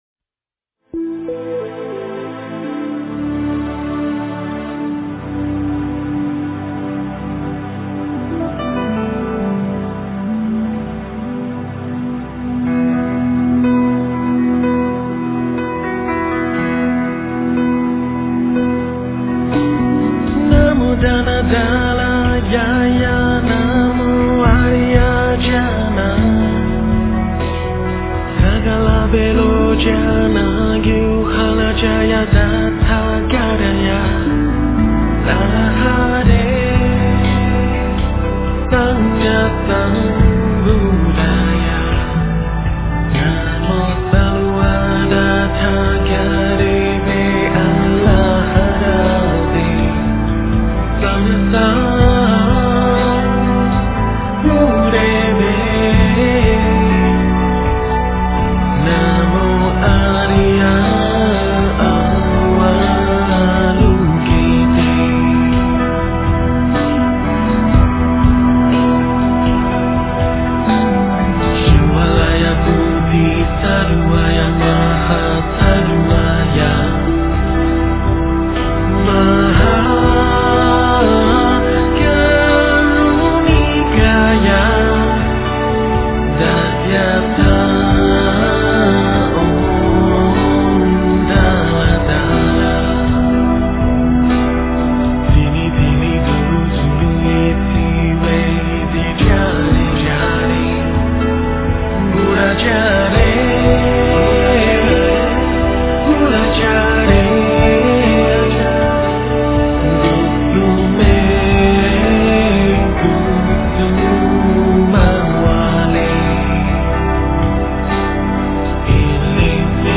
诵经
佛音 诵经 佛教音乐 返回列表 上一篇： 南无本师释迦牟尼佛 下一篇： 般若波罗蜜多心经 相关文章 佛说十二佛名神咒校量功德除障灭罪经 佛说十二佛名神咒校量功德除障灭罪经--佛经...